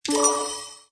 correct-6033.wav